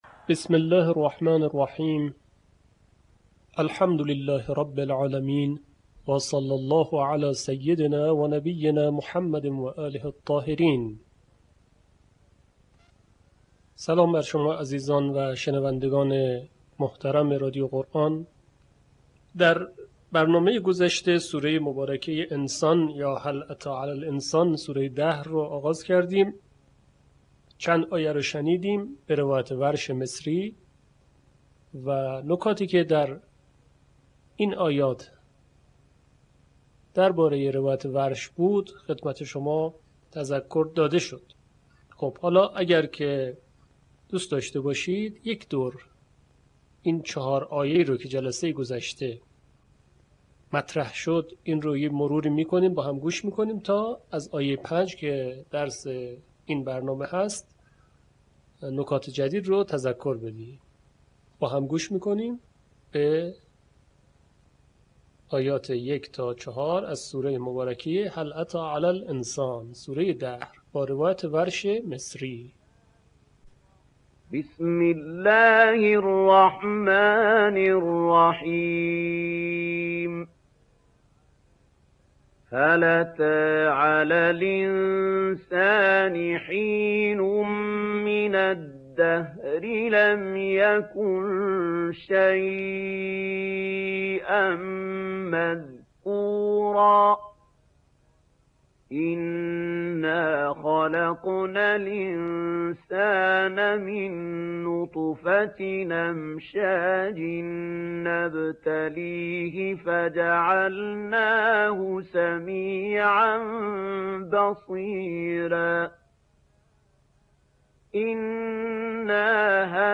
صوت | آموزش آیاتی از سوره دهر به روایت ورش